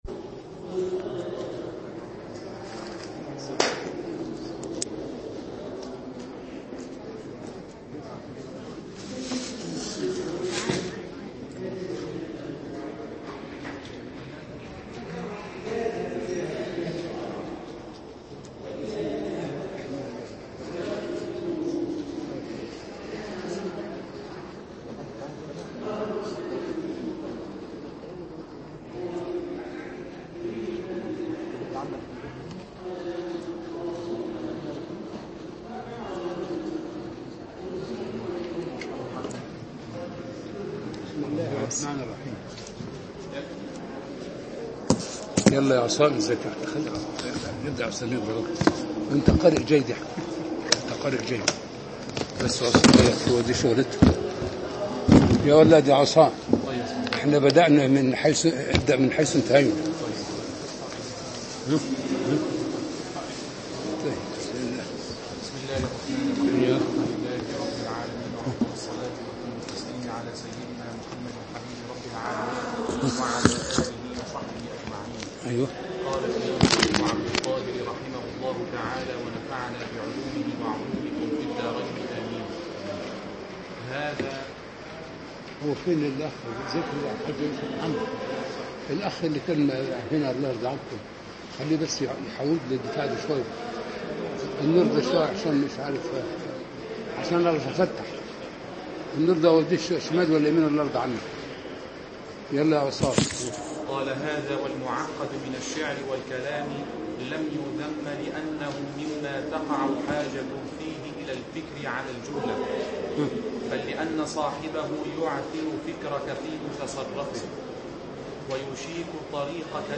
عنوان المادة الدرس السادس والخمسون (شرح كتاب أسرار البلاغة) تاريخ التحميل الأربعاء 21 سبتمبر 2016 مـ حجم المادة 20.67 ميجا بايت عدد الزيارات 752 زيارة عدد مرات الحفظ 287 مرة إستماع المادة حفظ المادة اضف تعليقك أرسل لصديق